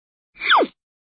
宇宙飞船气动门